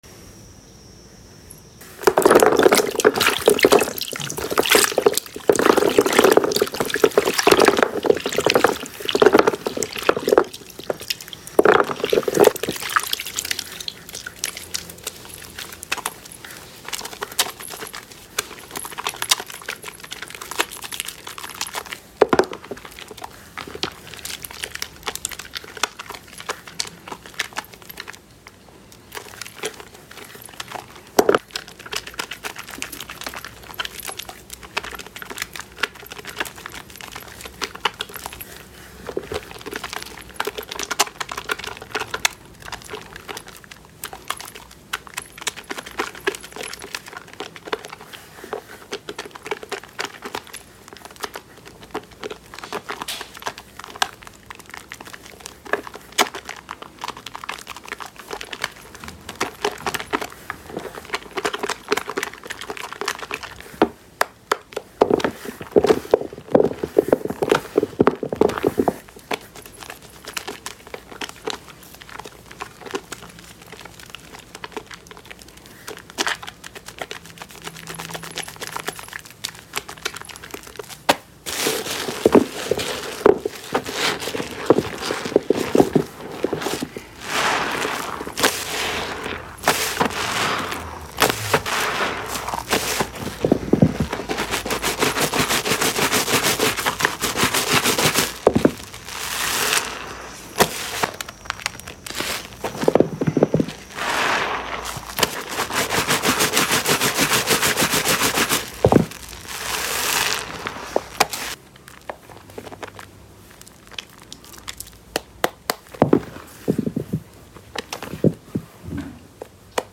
ASMR WASHING PUPRPLE SOAP 💜💜💜💜 Sound Effects Free Download